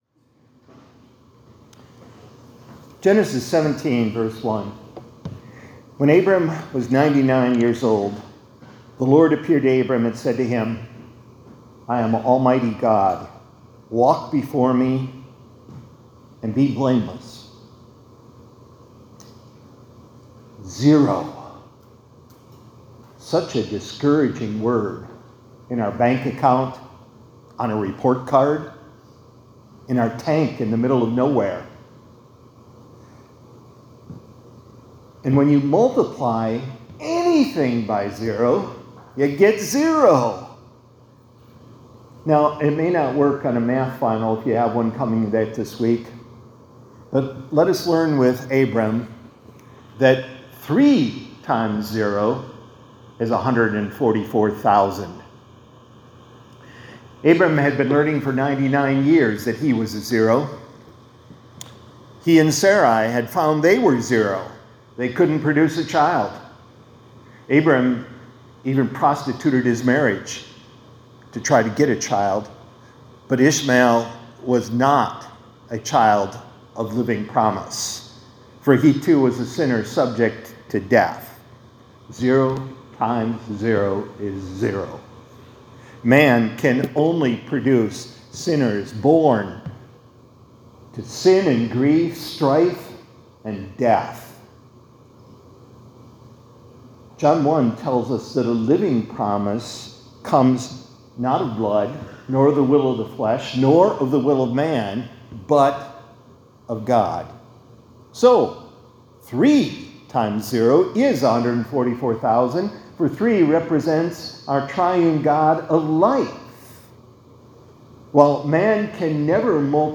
2025-05-22 ILC Chapel — 3 x 0 = 144,000